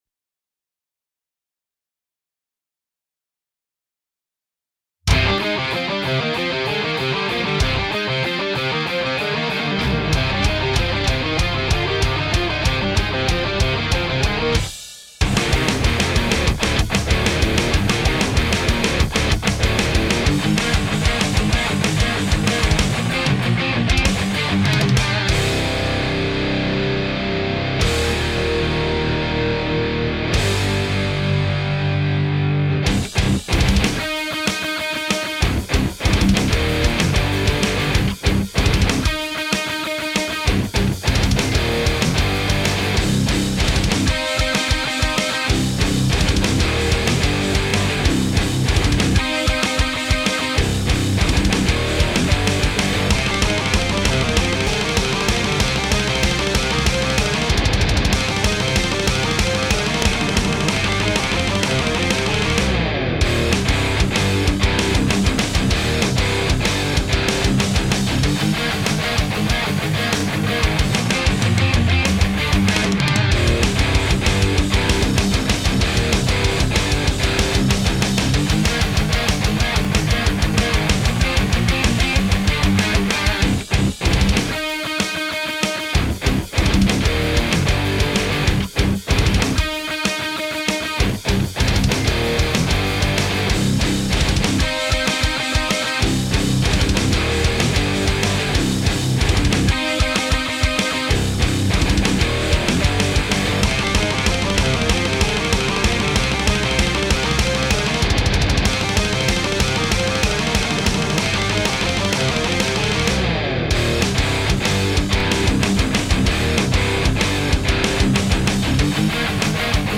Ich hab mir mal den Spaß erlaubt, und die beiden Versionen per EQ angeglichen.
Der komplette Song, alle paar Sekunden erfolgt der Wechsel zwischen den Spuren.